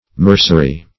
Meaning of mercery. mercery synonyms, pronunciation, spelling and more from Free Dictionary.
mercery.mp3